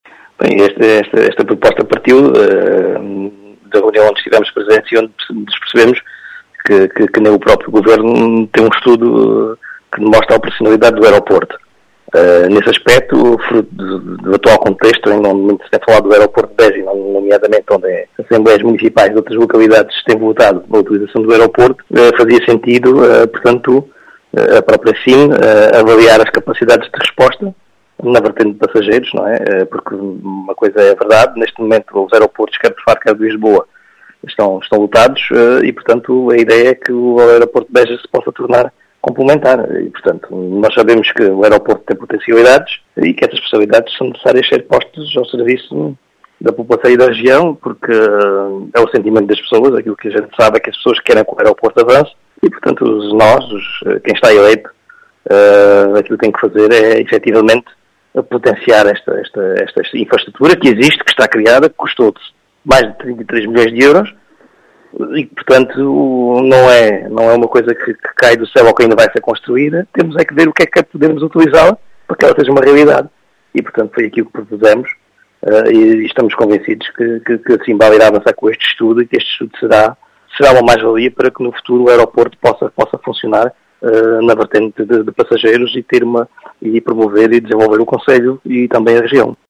Em declarações à Rádio Vidigueira, João Português, presidente da Câmara de Cuba, diz fazer sentido avaliar a capacidade de resposta na vertente de passageiros, tendo em conta a actual situação dos aeroportos de Lisboa e Faro, dizendo que este estudo será uma “mais valia” para o Aeroporto de Beja.